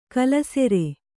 ♪ kallasere